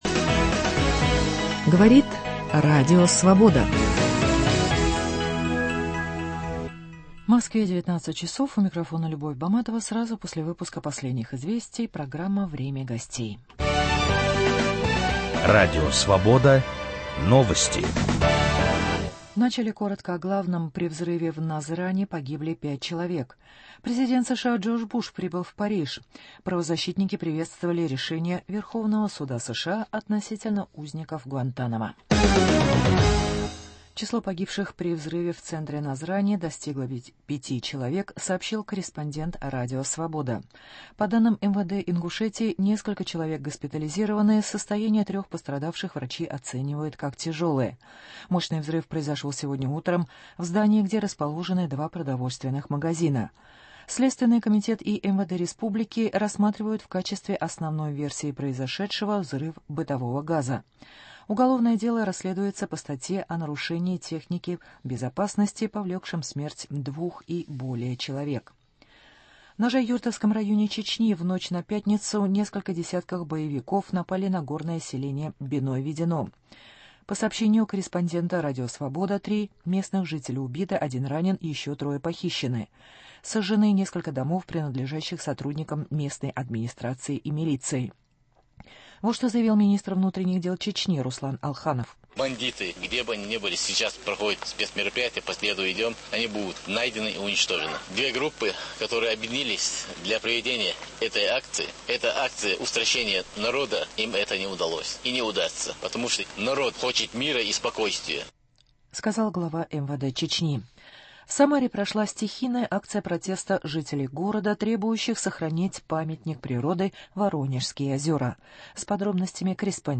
Виталий Портников обсуждает с бывшим командующим ВМС Украины Владимиром Бескоровайным ситуацию вокруг Черноморского флота